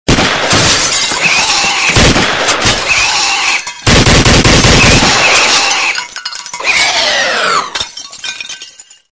Звуки антивируса Касперского
Звук, созданный в лаборатории Касперского